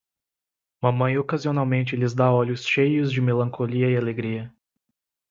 /me.lɐ̃.koˈli.ɐ/